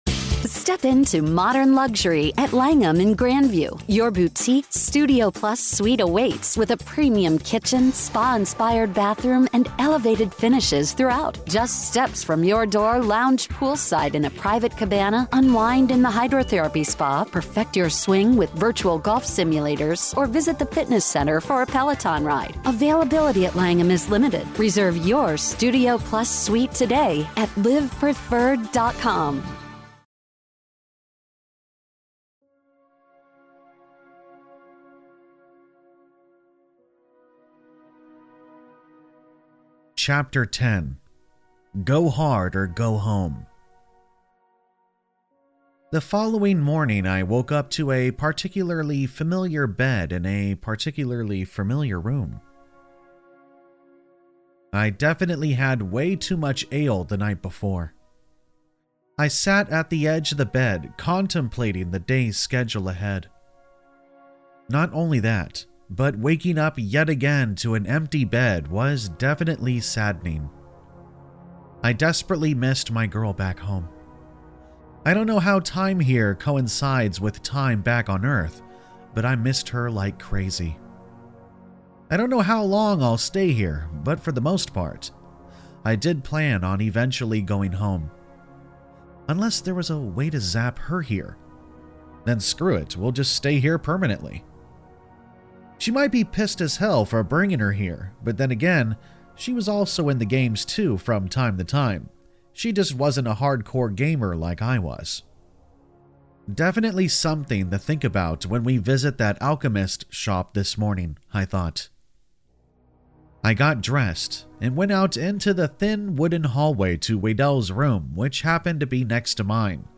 Story Credit - Written & Narrated by Campfire Tales